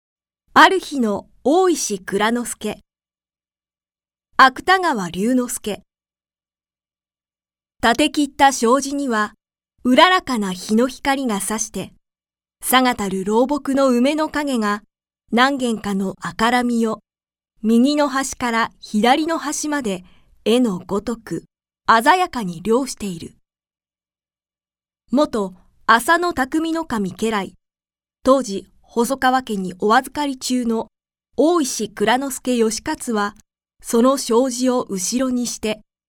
朗読ＣＤ　朗読街道１４４「或日の大石内蔵助・運」芥川龍之介
朗読街道は作品の価値を損なうことなくノーカットで朗読しています。